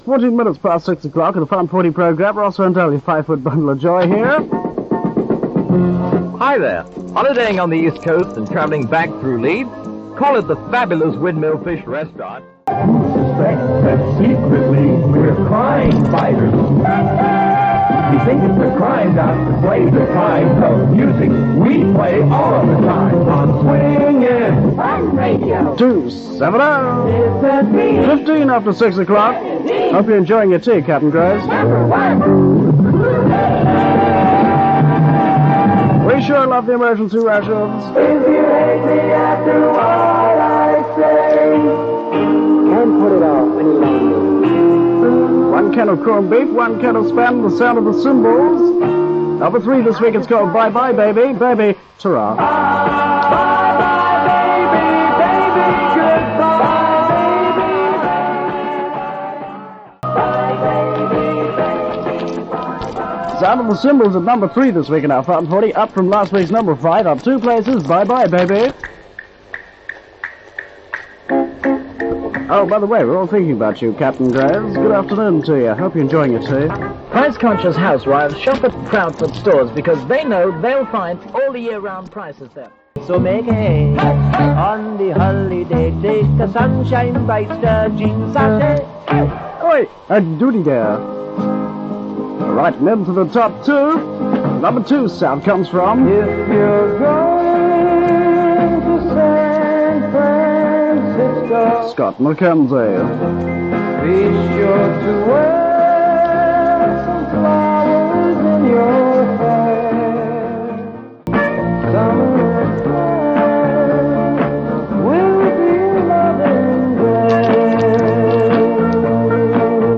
ending the Fun 40 Show on Radio 270, 30th July 1967.